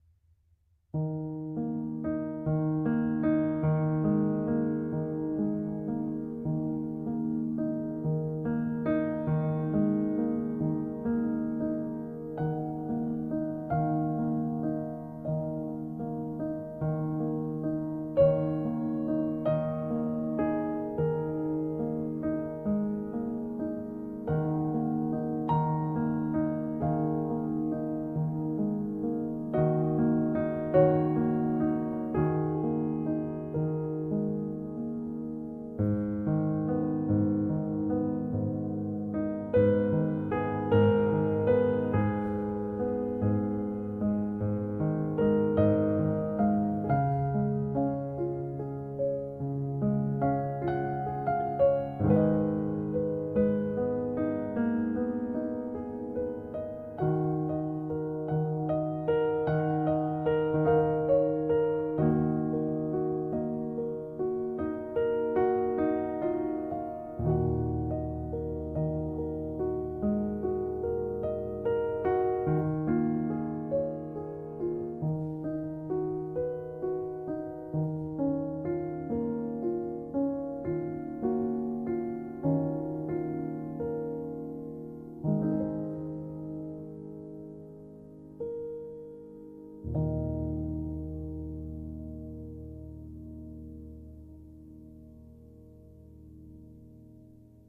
È un’ottima colonna sonora per una passeggiata, per un viaggio, ma anche semplicemente per stare lì e guardare un albero con le foglie che piano piano escono per la primavera.